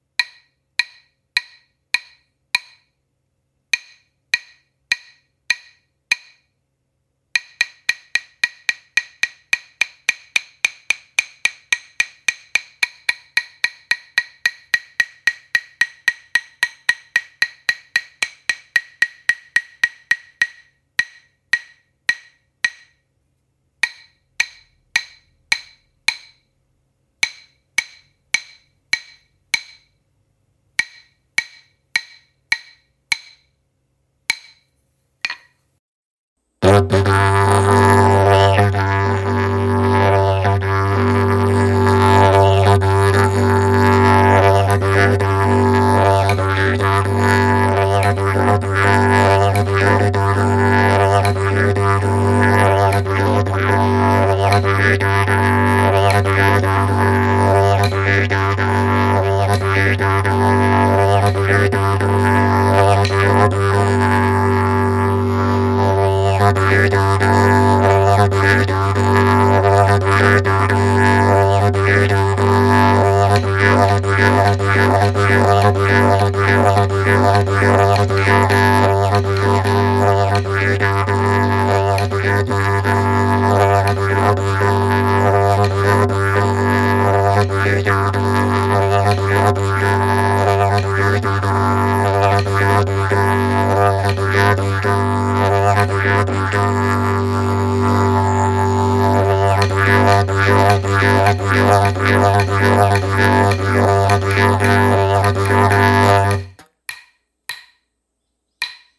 片方のスティックが細く、もう片方が薄手なせいか、よりライトで澄んだ金属音に響き、北東アーネム・ランドのBilmaのような力強さはないものの、Kenbiの響きにフィットする音質なのかもしれません。